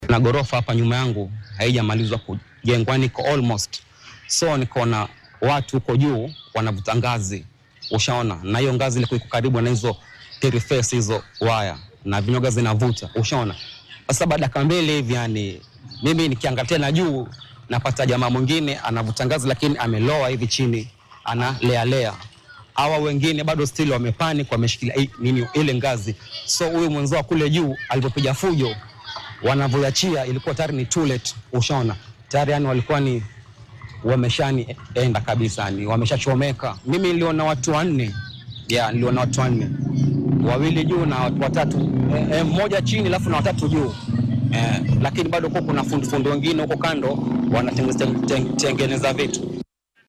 Hal qof ayaa ku dhintay saddex kalena way ku dhaawacmeen kadib dab ka kacay dhismo ku yaala Mombasa. Mid ka mid ah goobjooge ayaa ka warbixinaya sida ay wax u dhaceen.